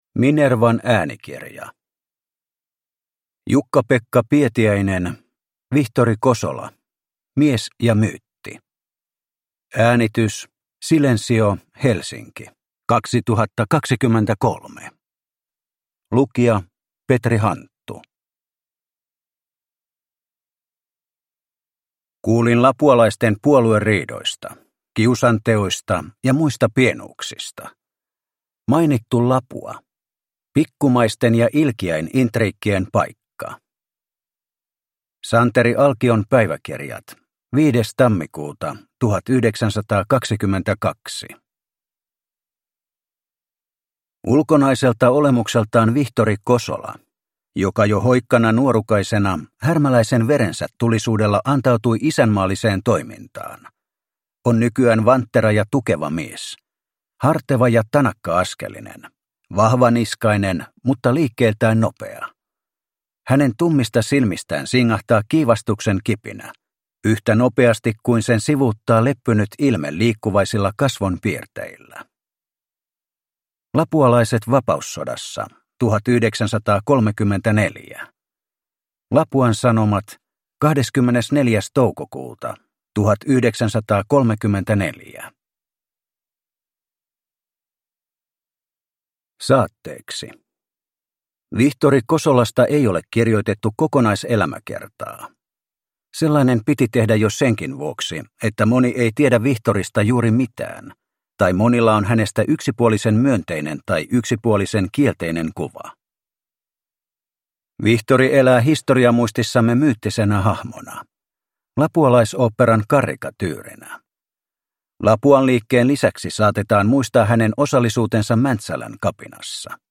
Vihtori Kosola - mies ja myytti – Ljudbok – Laddas ner